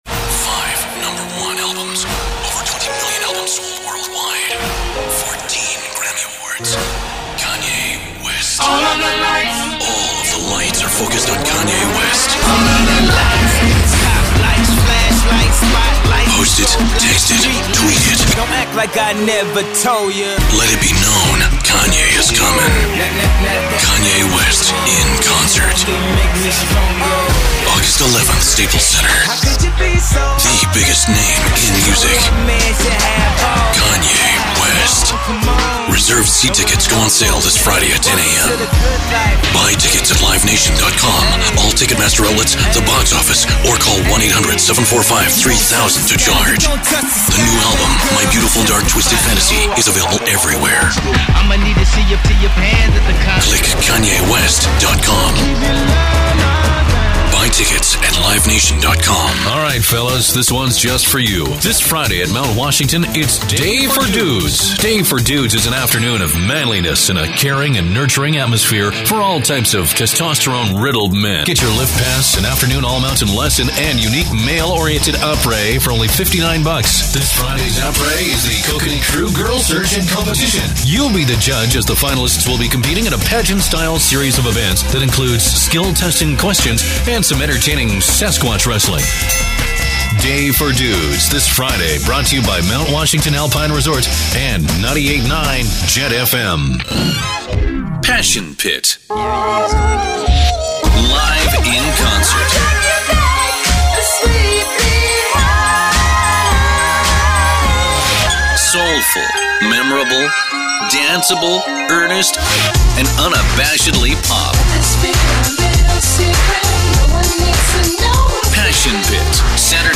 Male
My natural voice is easily described as deep, authoritative or commanding.
Music Promos
Energetic Concert Promos
1019Promos_demo_FULL.mp3